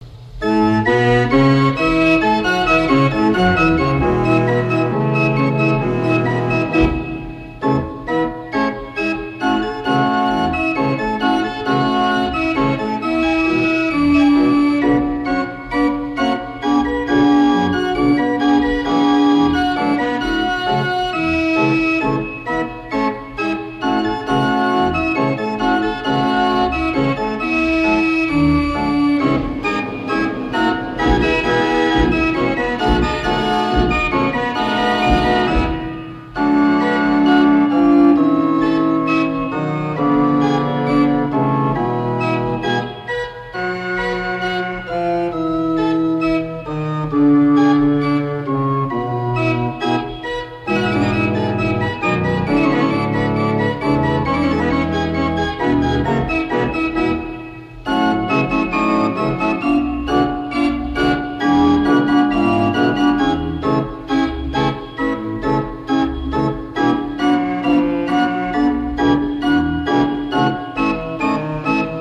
BAND ORGANS/FAIR ORGANS